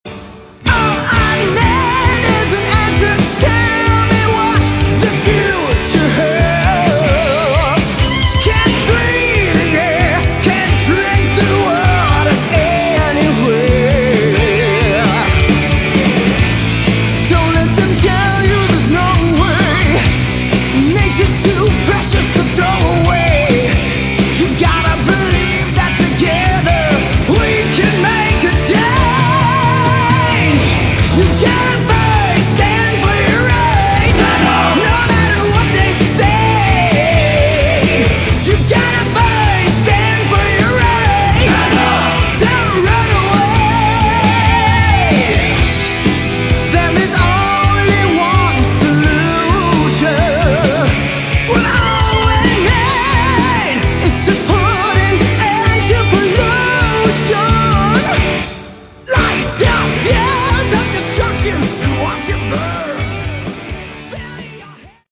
Category: Hard Rock
lead vocals, guitars
keyboards, backing vocals
drums, backing vocals
bass, backing vocals